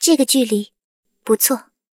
追猎者开火语音2.OGG